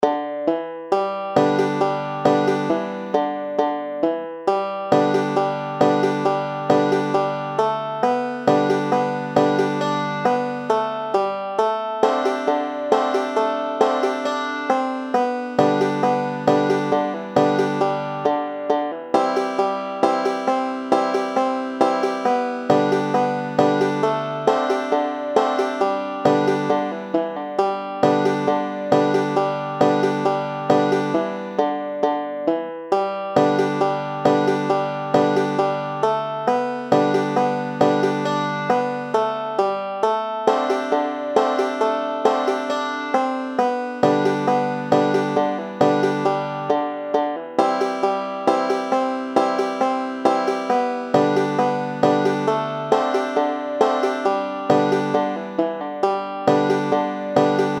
Songbuch – She’ll Be Coming ‚Round The Mountain Banjo TAB